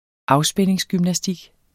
Udtale [ ˈɑwˌsbεnˀeŋs- ]